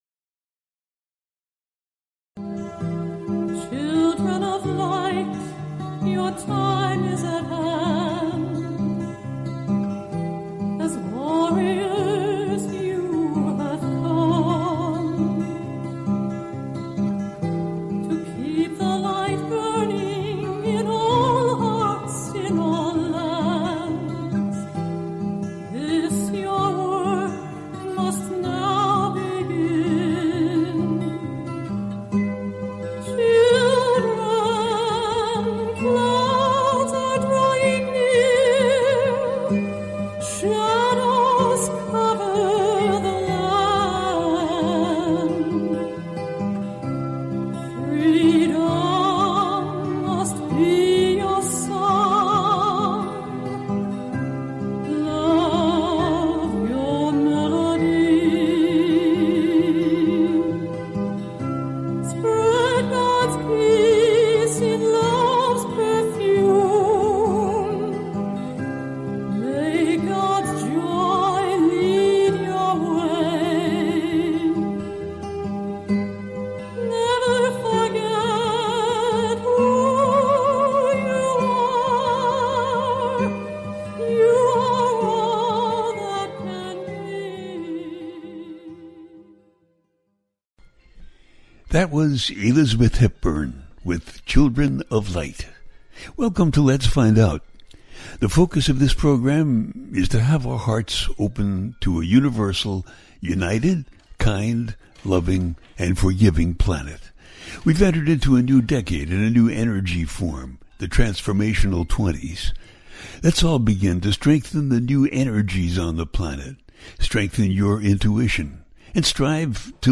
Subscribe Talk Show
The listener can call in to ask a question on the air.
Each show ends with a guided meditation.